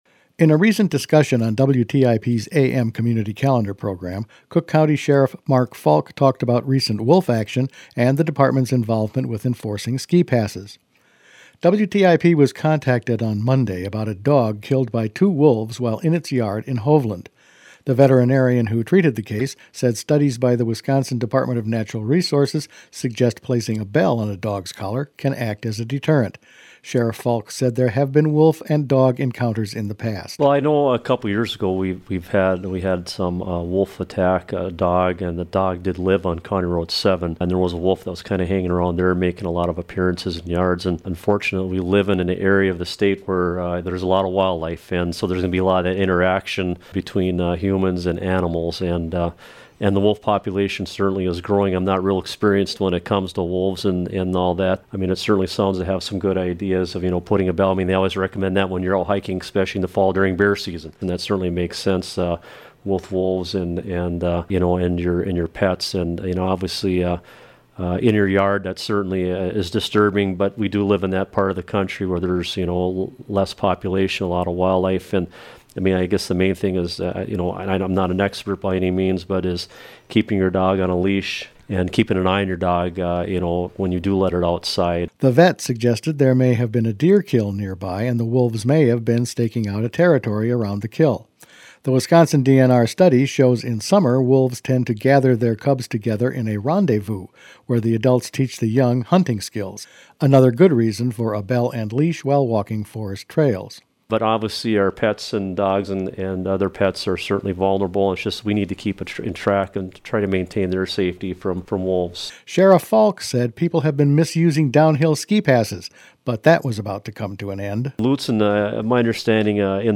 Sheriff Mark Falk recently talked about wolf-dog encounters and the fact that an increasing number of people are cheating on their downhill ski passes.